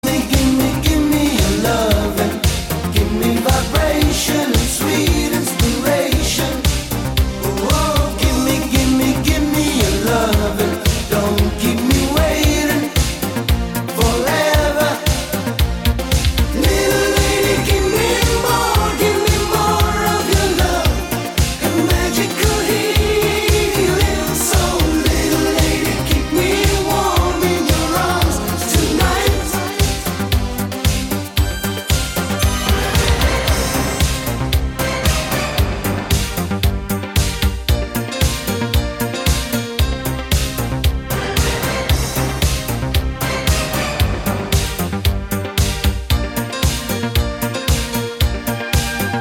Eurodance
europop
дискотека 80-х
Ретро хит 80-90-х годов